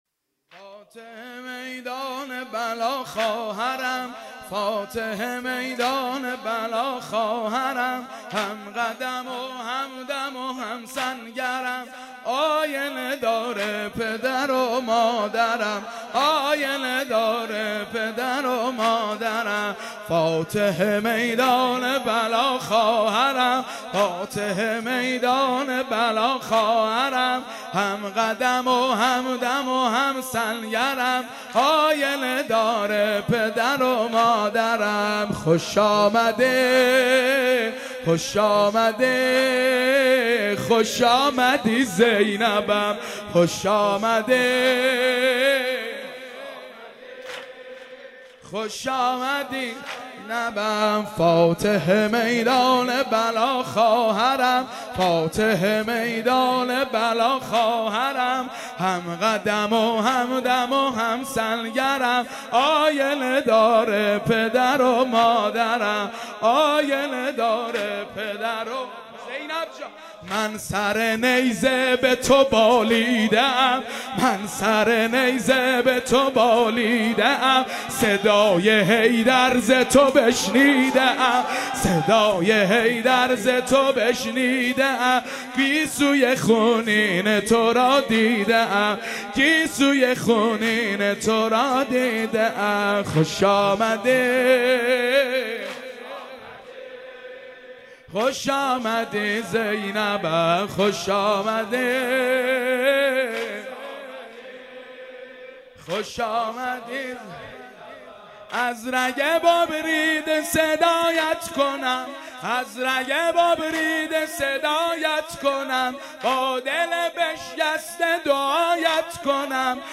اربعین - واحد - فاتح میدان بلا خواهرم
مداحی